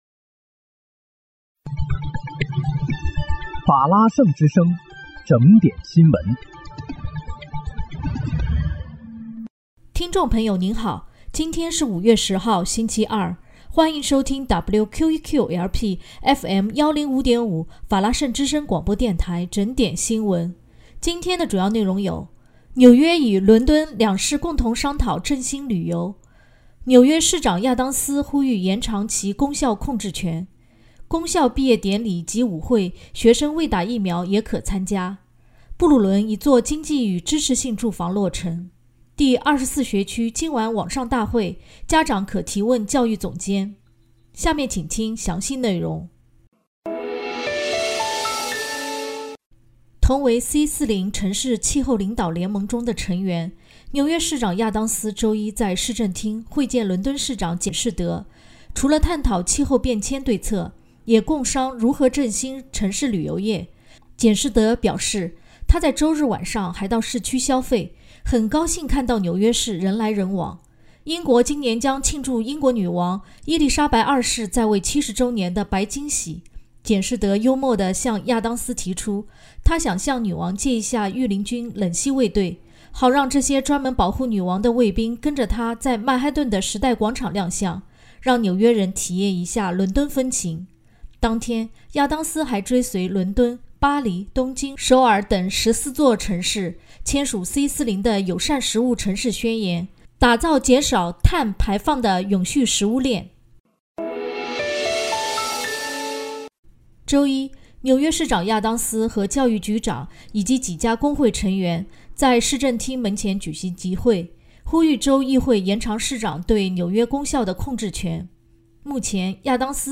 5月10日（星期二）纽约整点新闻
听众朋友您好！今天是5月10号，星期二，欢迎收听WQEQ-LP FM105.5法拉盛之声广播电台整点新闻。